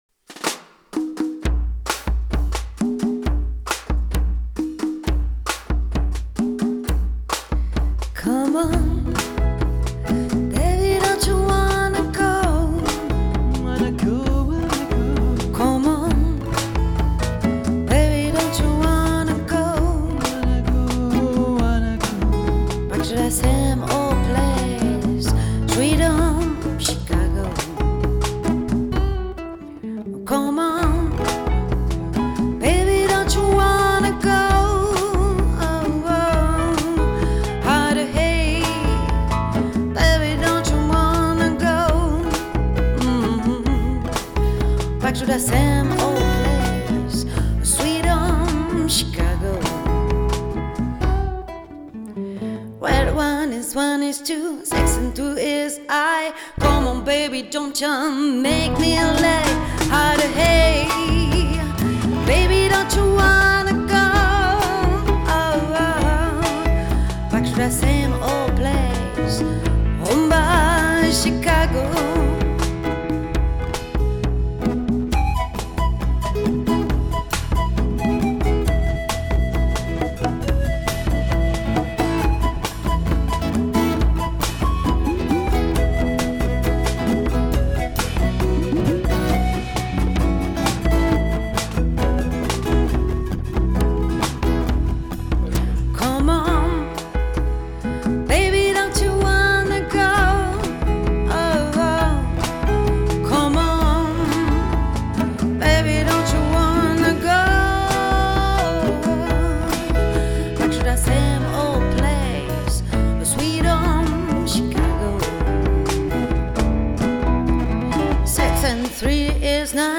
Découvrez notre sélection de reprises et compos soigneusement enregistrées en studio
Latin-Jazz